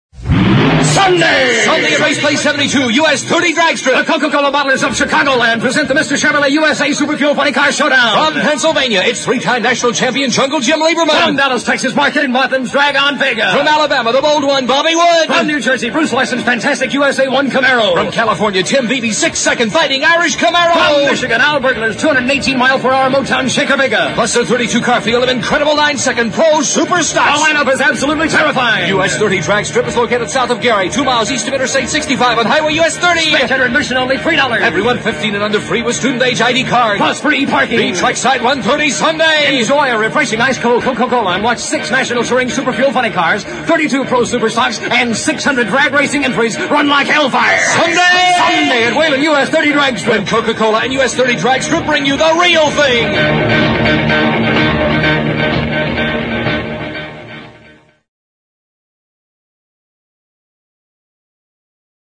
Drag Strip Radio Spots